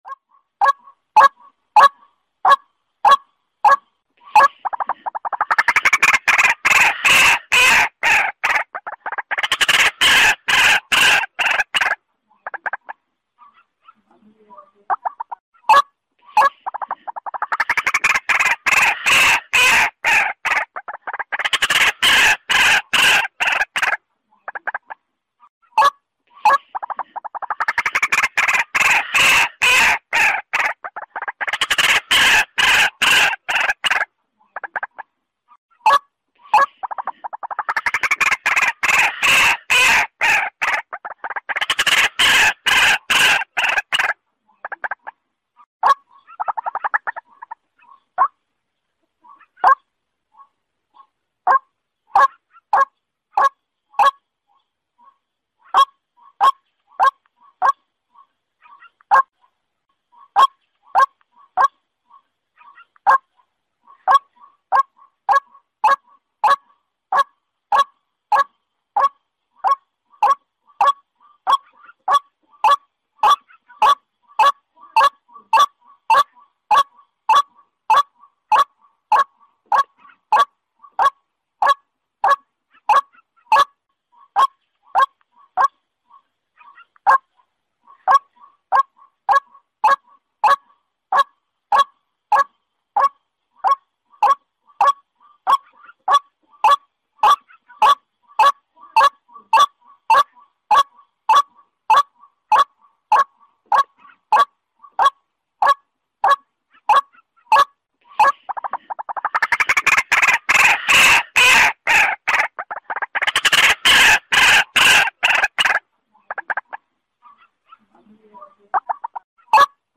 Suara Ruak Ruak Betina
Suara Burung Ruak-Ruak Jernih Durasi Panjang Suara Burung Ruak Ruak Bersih Jernih
Kategori: Suara burung
Keterangan: Dapatkan koleksi suara burung Ruak Ruak Betina yang jernih dan durasi panjang, dengan total durasi 3 jam dalam format MP3.